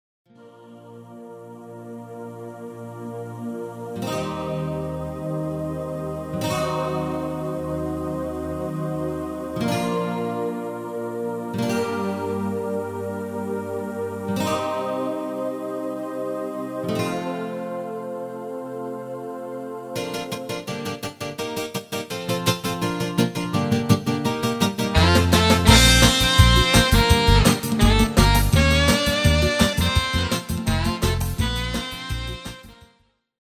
Demo/Koop midifile
Genre: Evergreens & oldies
- Géén vocal harmony tracks
Demo's zijn eigen opnames van onze digitale arrangementen.